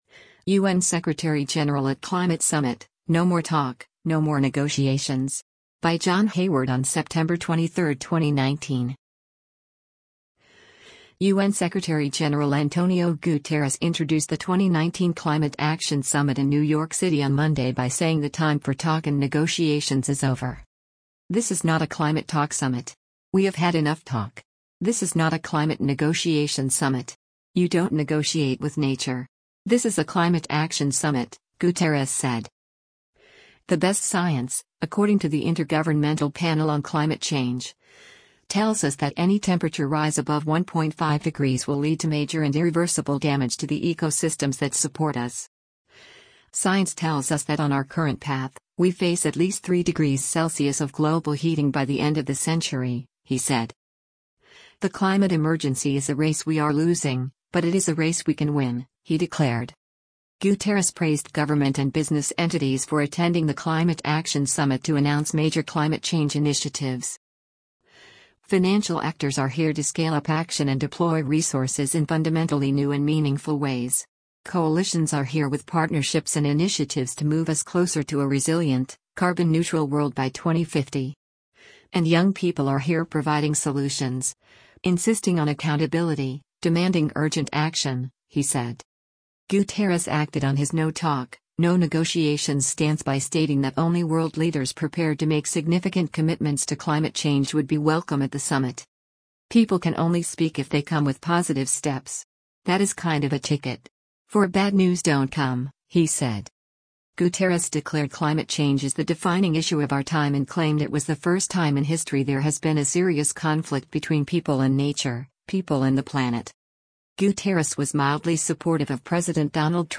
United Nations (U.N.) Secretary-General Antonio Guterres speaks at the summit to address c